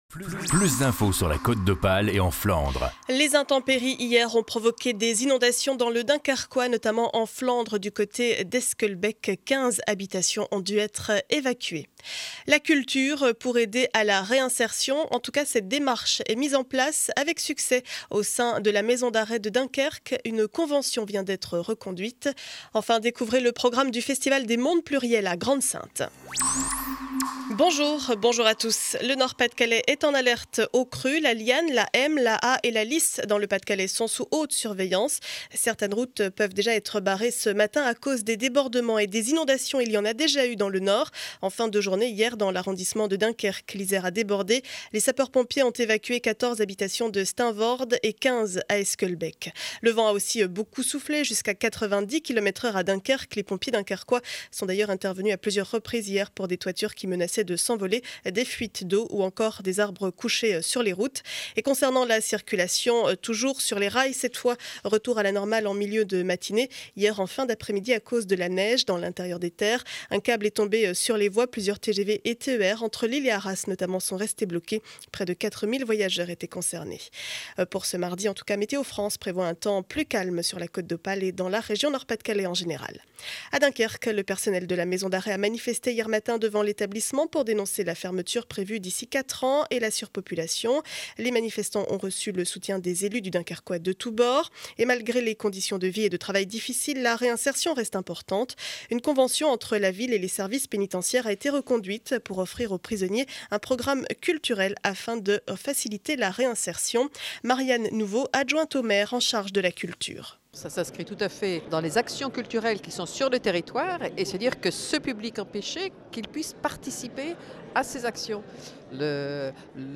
Journal du mardi 6 mars 2012 7 heures 30 édition du Dunkerquois.